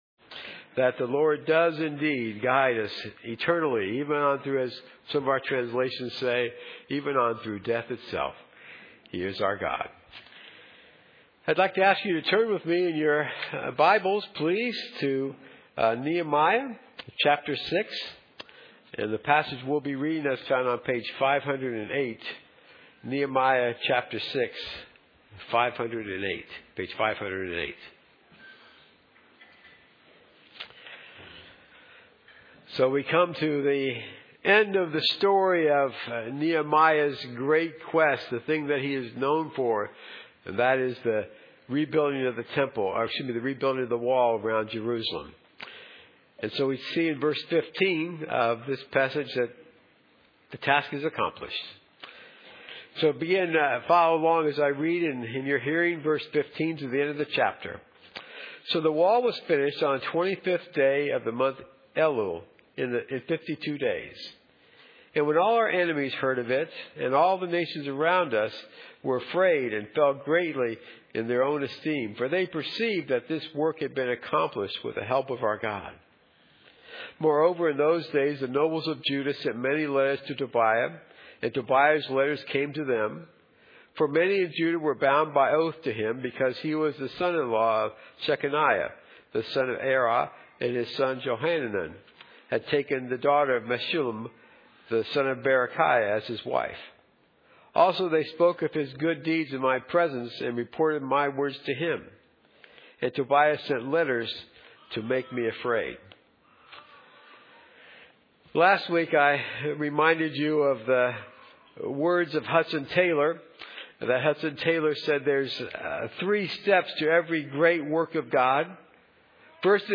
Nehemiah 6:15-19 Service Type: Morning Theme